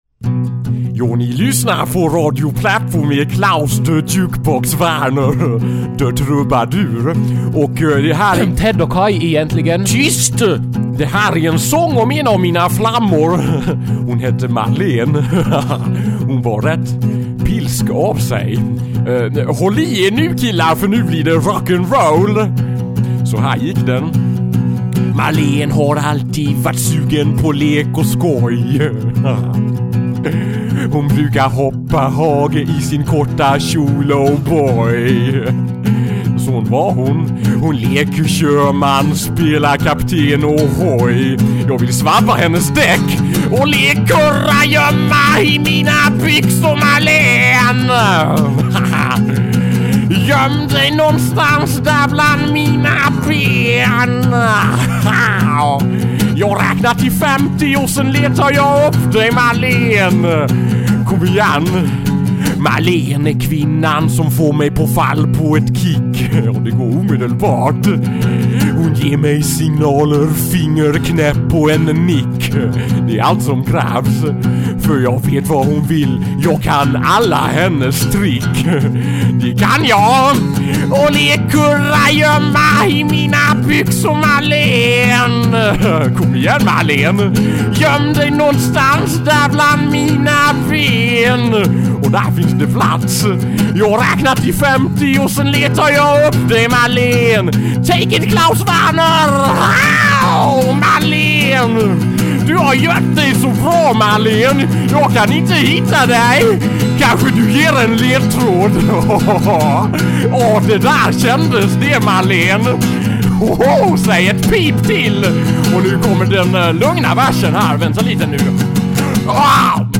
Dessutom i stereo!